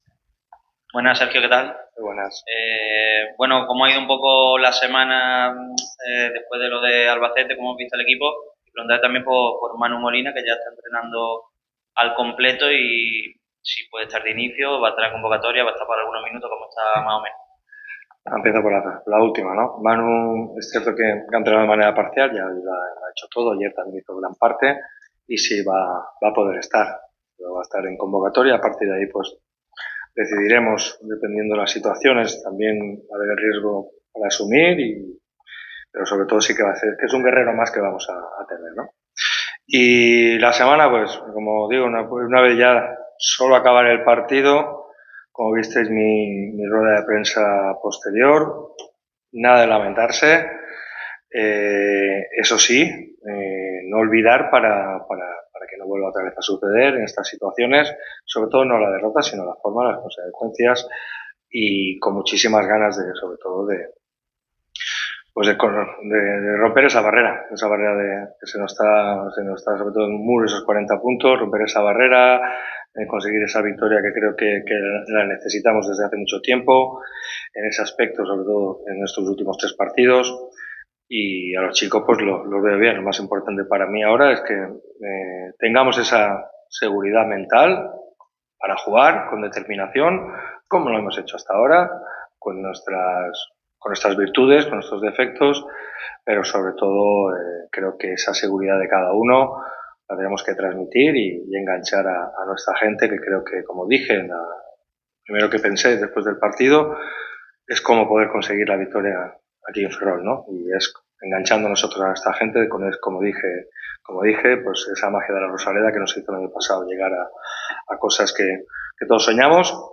El entrenador del Málaga CF ha comparecido ante los medios en la previa del duelo que enfrentará a los malaguistas contra el Racing de Ferrol este próximo